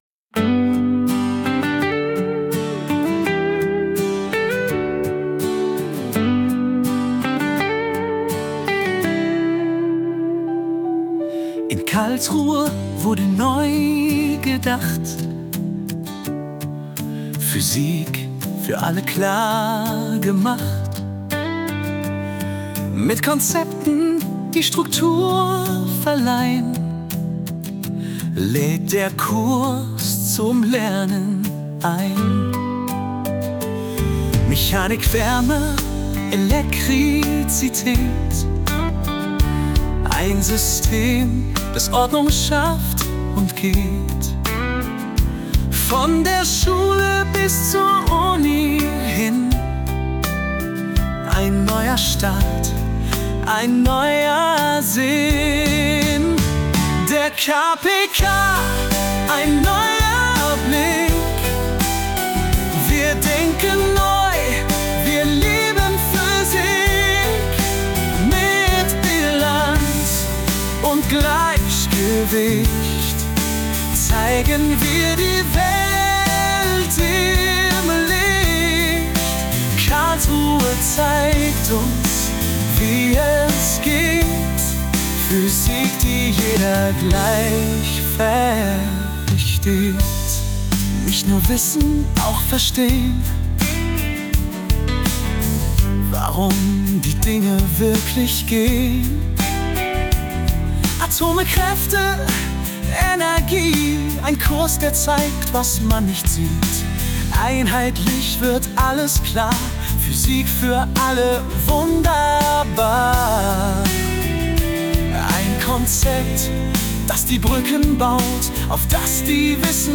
– KPK-Song (KI-generiert)  (5,7 MB)